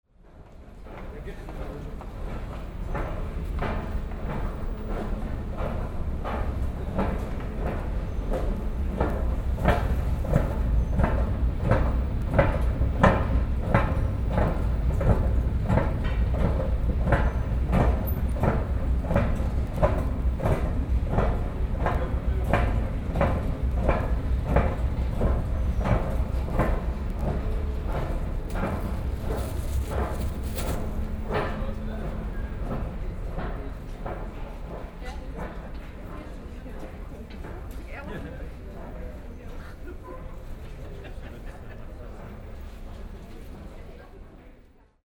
Paris – Moving Stairways – Ears Are Wings
moving stairways sound, people talking
12_paris_stairway.mp3